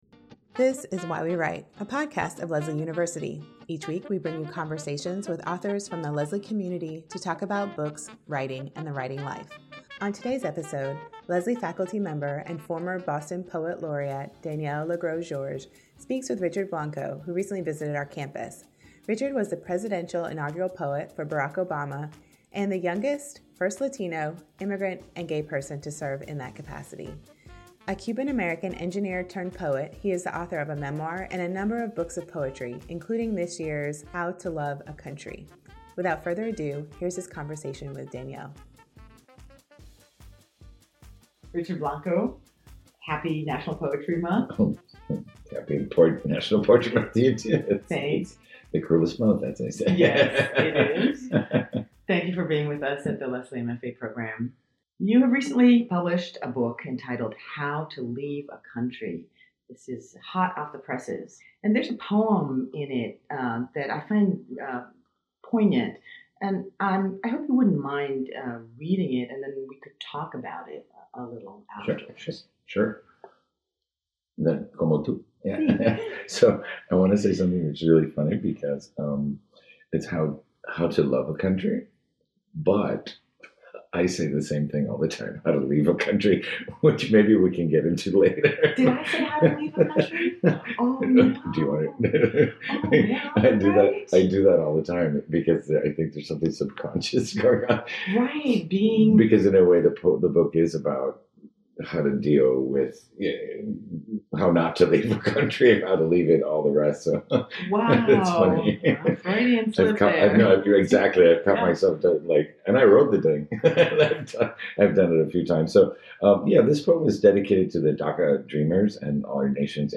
Richard Blanco is the fifth presidential inaugural poet in U.S. history—the youngest, first Latino, immigrant, and gay person to serve in such a role.